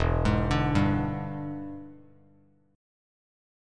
E7#9 chord